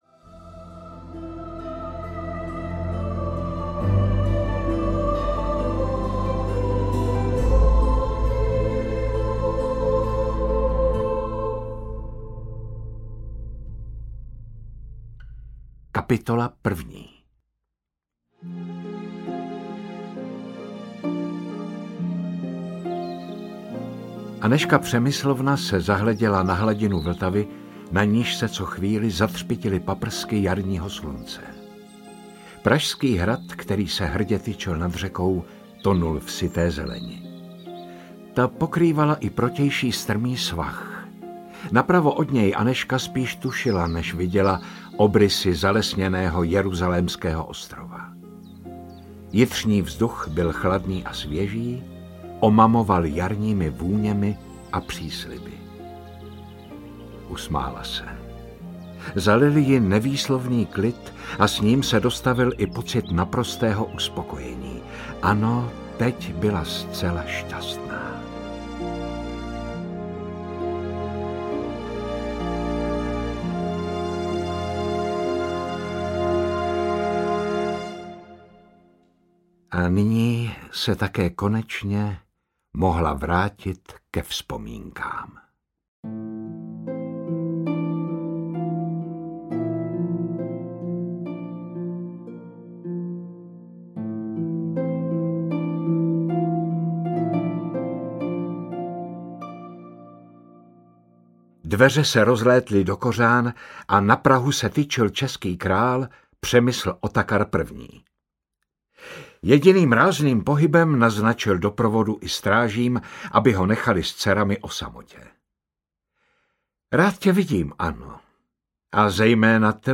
Anežka Česká audiokniha
Ukázka z knihy
• InterpretJitka Ježková, Jaromír Meduna